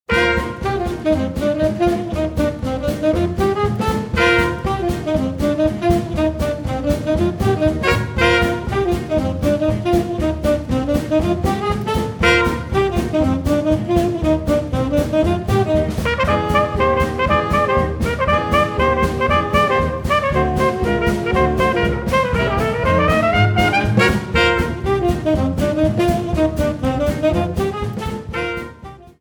trumpet, vocals
clarinet, tenor sax
alto sax
piano
guitar
bass
drums